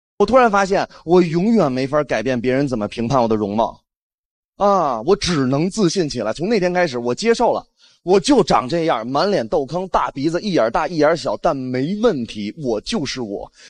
1.原音频：付航脱口秀
付航脱口秀.mp3